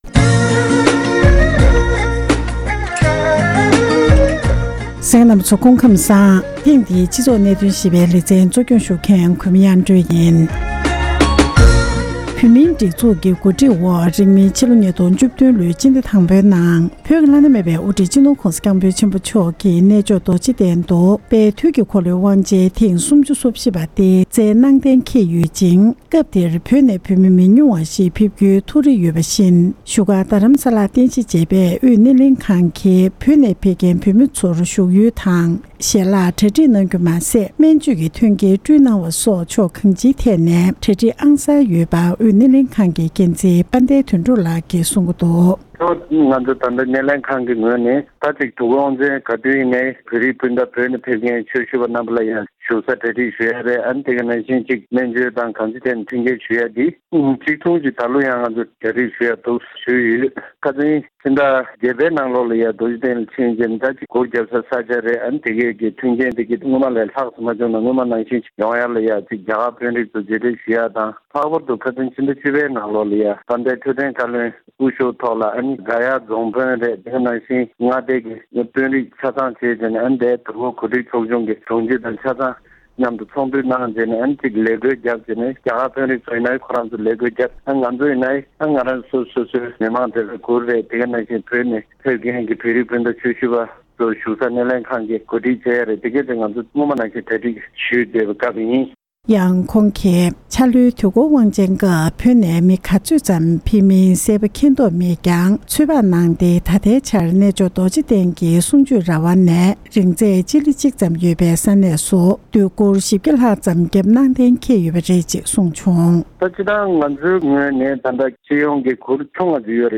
སྒྲ་ལྡན་གསར་འགྱུར།
འབྲེལ་ཡོད་མི་སྣར་བཅར་འདྲི་གནང་སྟེ